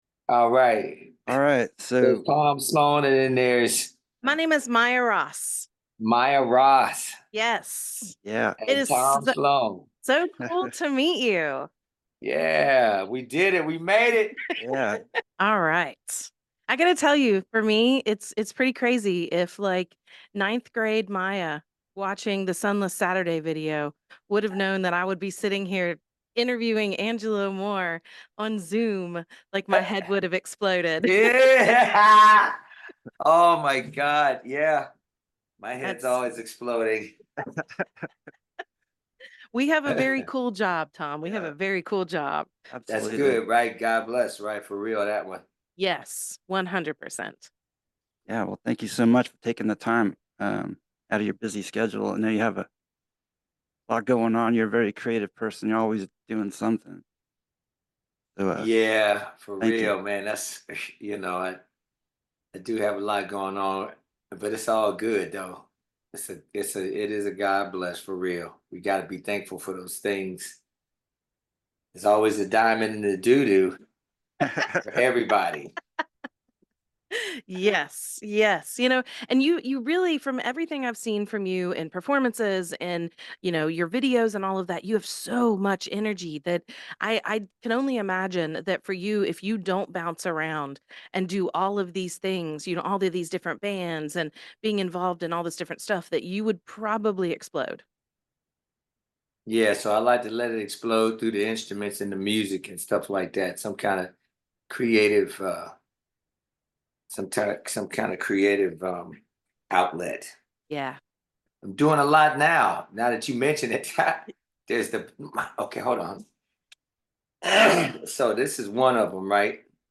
Angelo Moore Interview WTSQ 88.1 FM